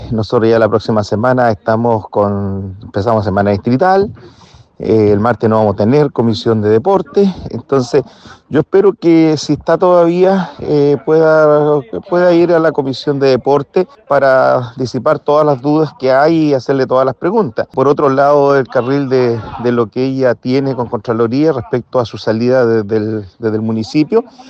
Finalmente, el diputado independiente de la bancada PPD, Cristián Tapia, si bien advirtió sobre lo estrecho de los los plazos, también resaltó la importancia de una aclaración por parte del ejecutivo, agregando que espera que la subsecretaria pueda asistir a la comisión.